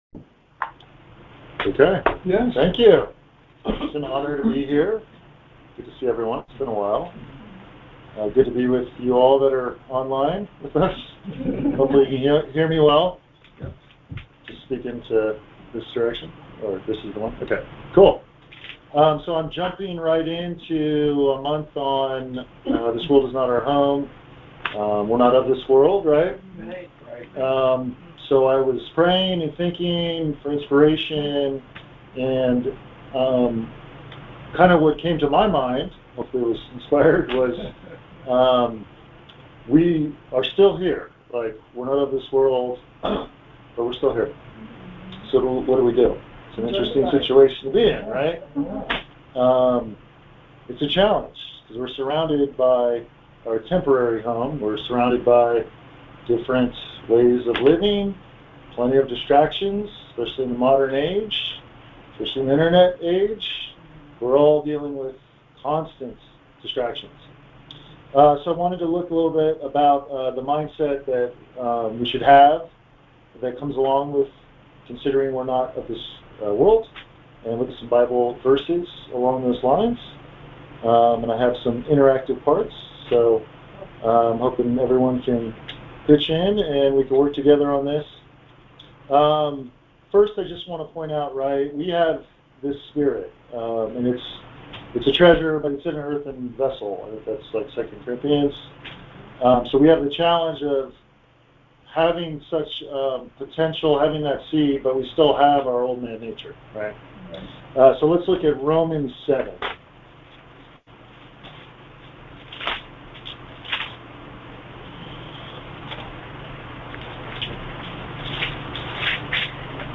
This World Is Not Our Home-Mindset Challenge Details Series: Conference Call Fellowship Date: Wednesday, 26 July 2023 Hits: 501 Play the sermon Download Audio ( 8.92 MB )